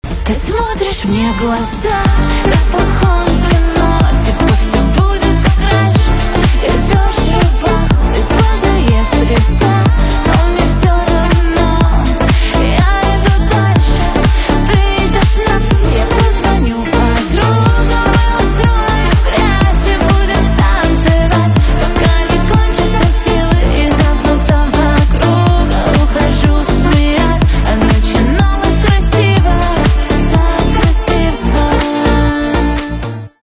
поп
ремиксы , танцевальные
басы
грустные